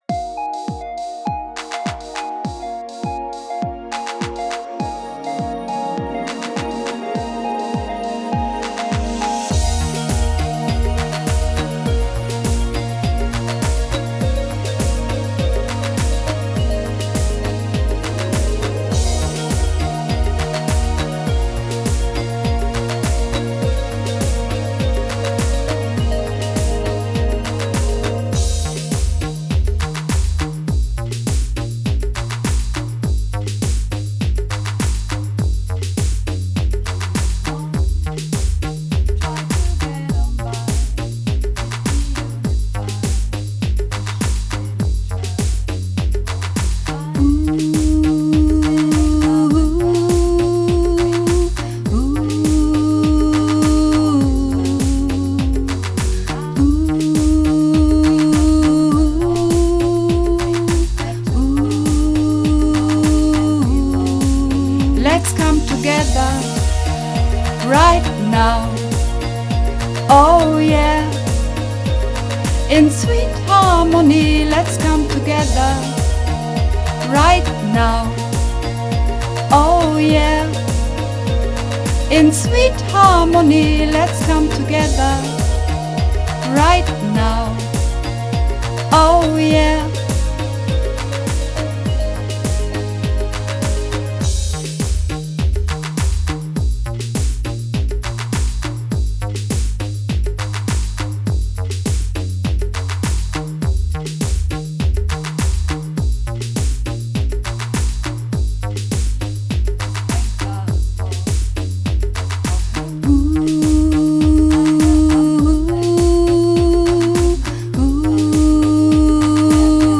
Übungsaufnahmen - Sweet Harmony
Sweet Harmony (Sopran 2)